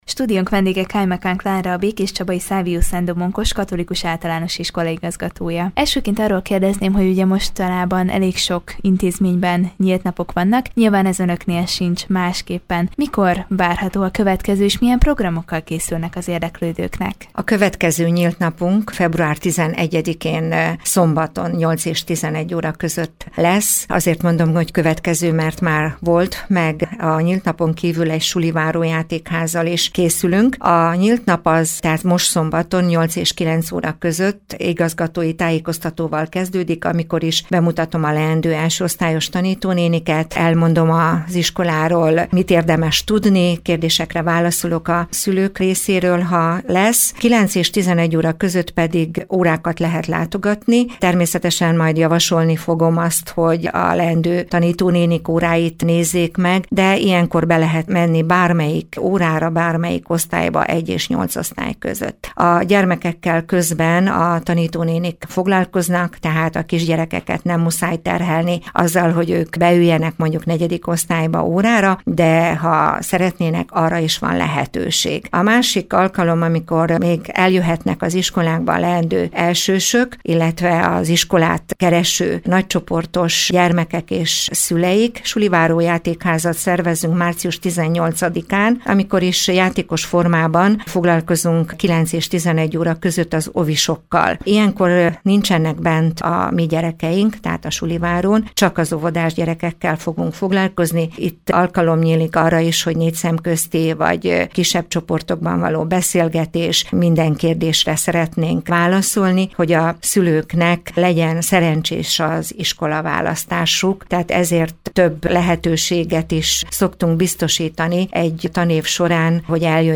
Erről beszélgetett tudósítónk az iskola igazgatójával.